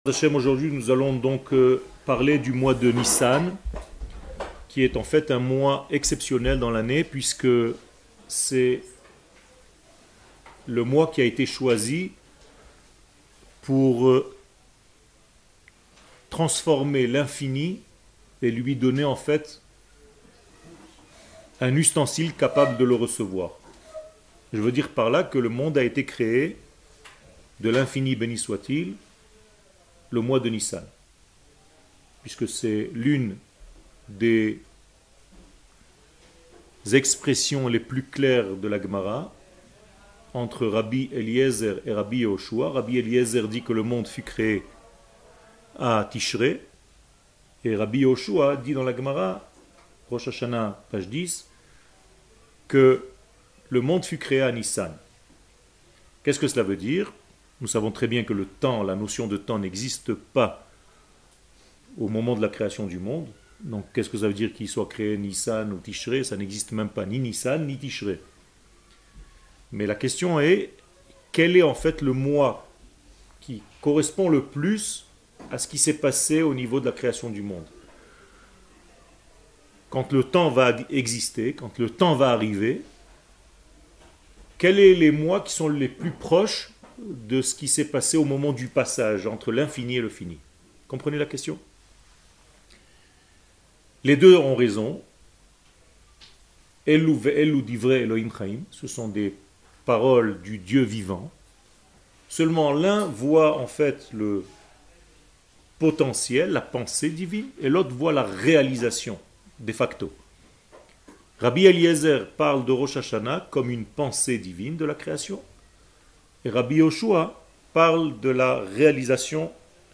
קטגוריה Le mois de Nissan 00:56:34 Le mois de Nissan שיעור מ 23 מרץ 2023 56MIN הורדה בקובץ אודיו MP3